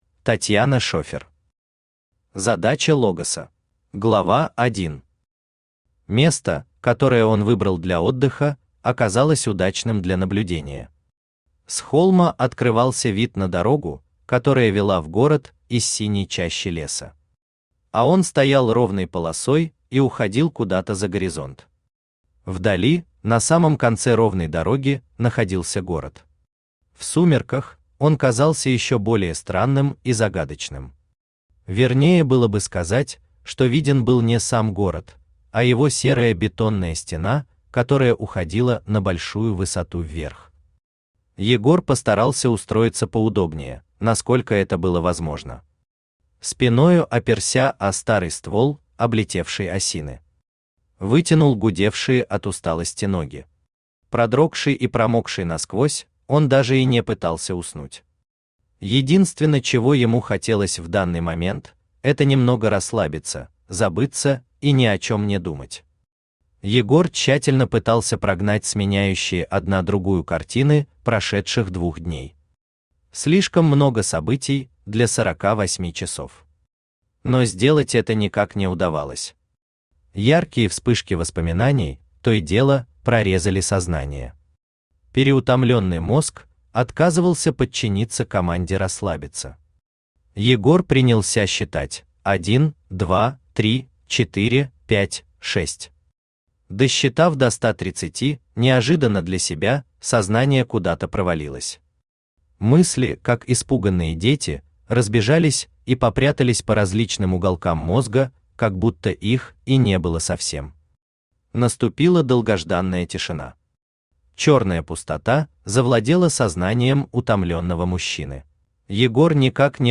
Аудиокнига Задача «Логоса» | Библиотека аудиокниг
Aудиокнига Задача «Логоса» Автор Татьяна Шефер Читает аудиокнигу Авточтец ЛитРес.